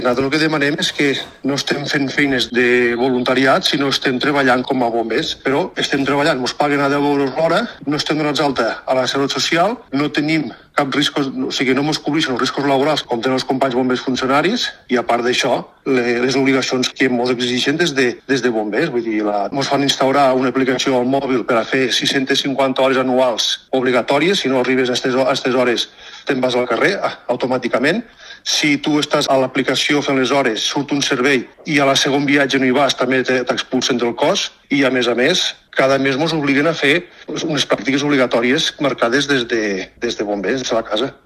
[ENTREVISTA] Els bombers voluntaris alcen la veu: «no és voluntariat, estem treballant com a bombers»